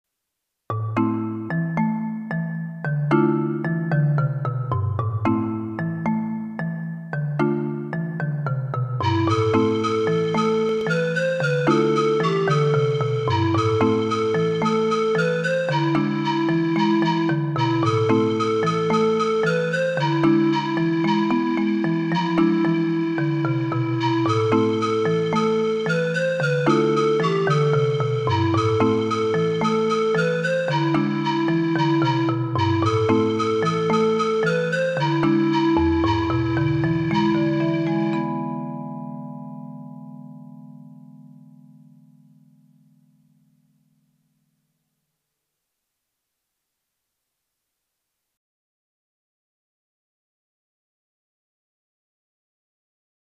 It was here that we decided that in the interest of time the children would play non-tuned percussion instruments (adding an African sound) and I would create computerized accompaniments.
Accompaniment, MP3 Listen to the audio file